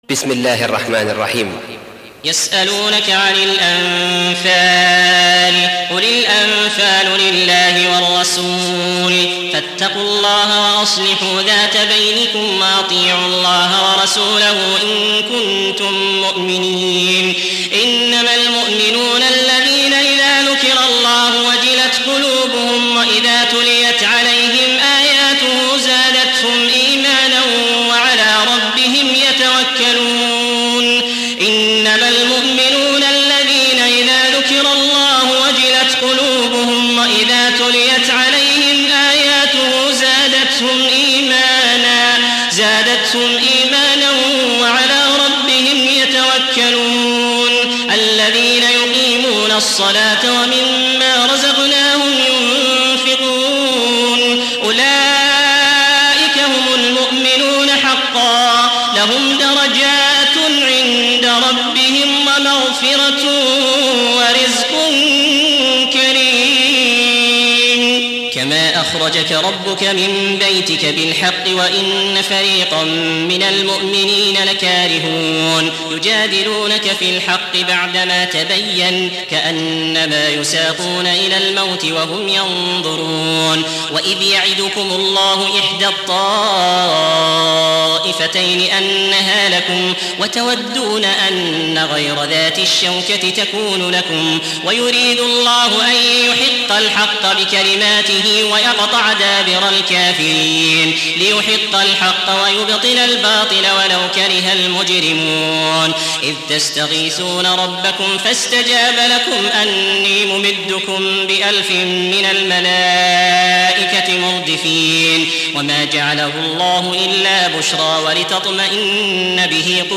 Surah Sequence تتابع السورة Download Surah حمّل السورة Reciting Murattalah Audio for 8. Surah Al-Anf�l سورة الأنفال N.B *Surah Includes Al-Basmalah Reciters Sequents تتابع التلاوات Reciters Repeats تكرار التلاوات